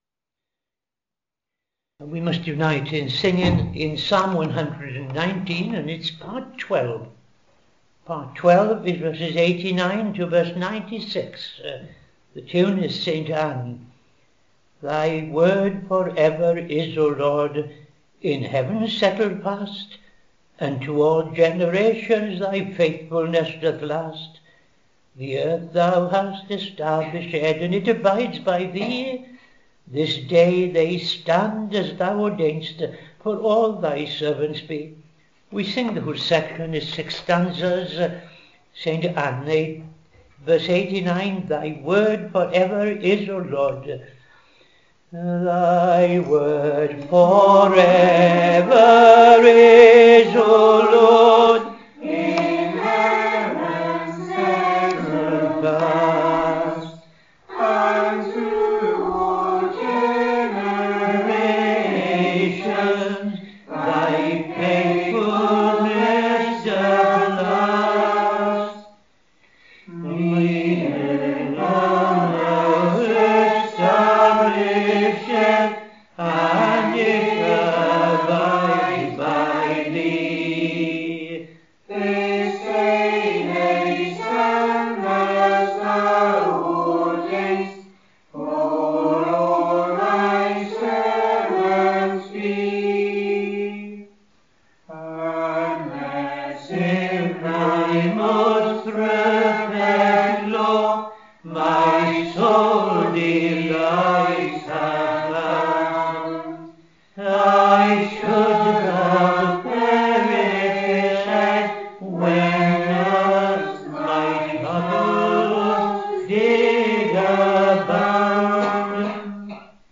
5.00 pm Evening Service Opening Prayer and O.T. Reading I Chronicles 11:20-47
Psalm 149:6-9 ‘Let in their mouth aloft be rais’d …’ Tune Graffenberg